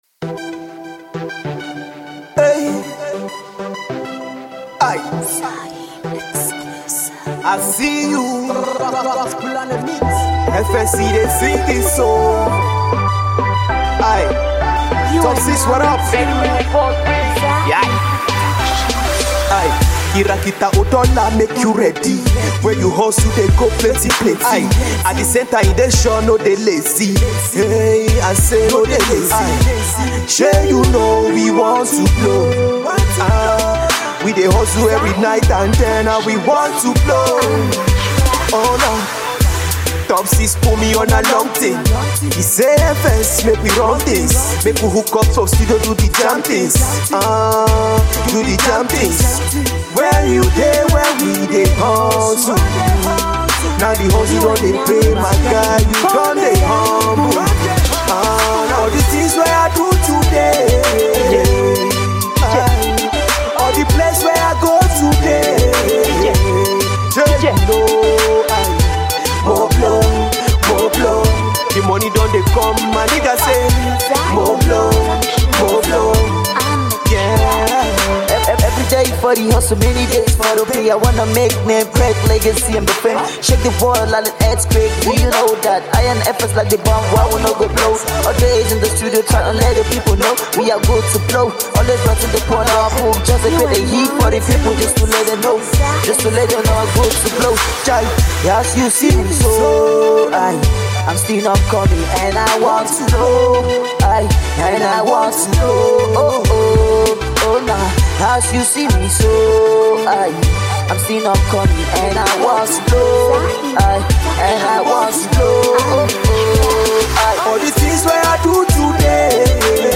afro hip hop